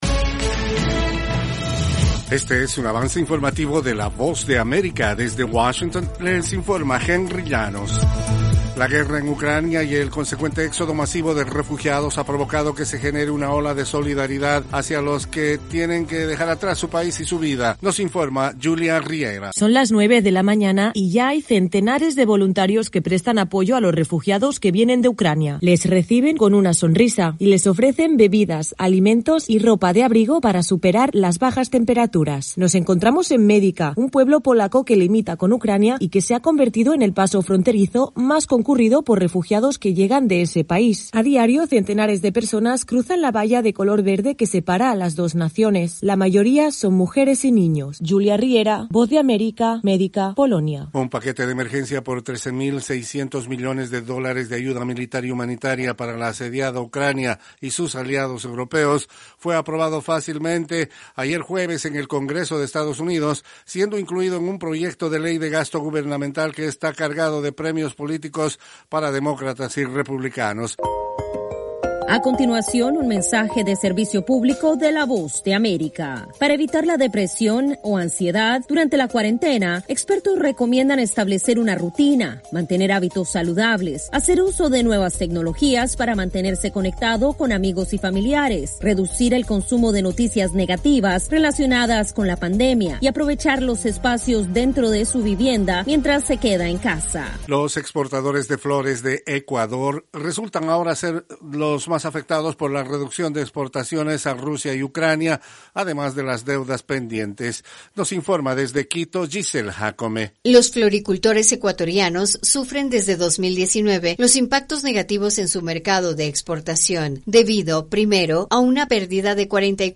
Cápsula informativa de tres minutos con el acontecer noticioso de Estados Unidos y el mundo. [9:00am Hora de Washington].